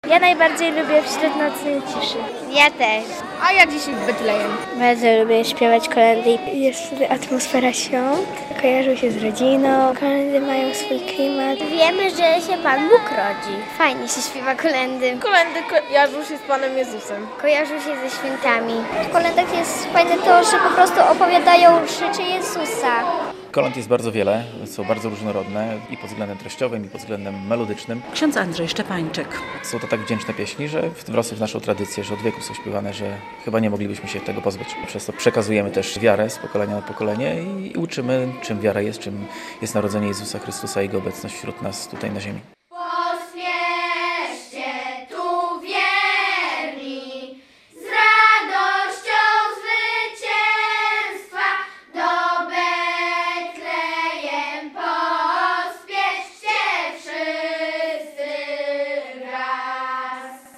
W sobotę (14.01) w Centrum Wystawienniczo-Konferencyjnym Archidiecezji Białostockiej odbył się Diecezjalny Przegląd Kolęd i Pastorałek.
Po prezentacjach regionalnych zespołów kolędniczych, odbyło się wspólne śpiewanie z gwiazdą - w tym roku był to Maciej Miecznikowski z formacji Leszcze.